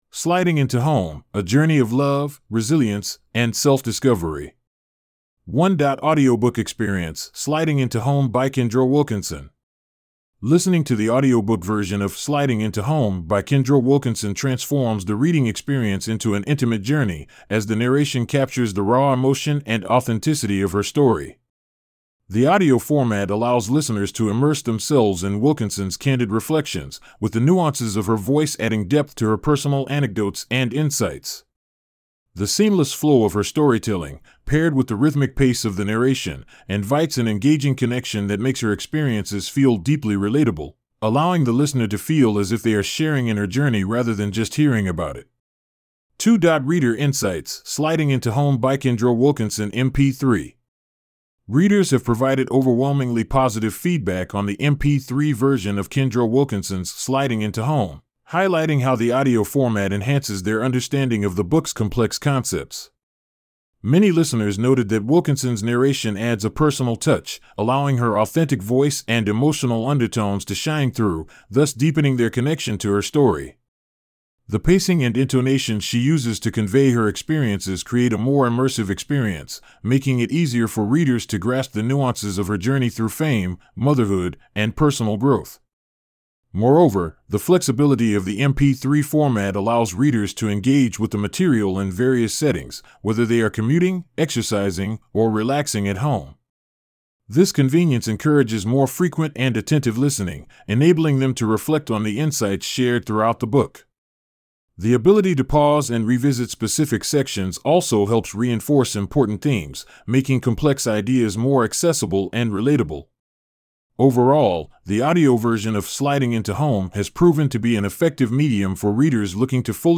1.Audiobook Experience:Sliding Into Home byKendra Wilkinson